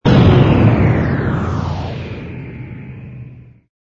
engine_bw_cruise_stop.wav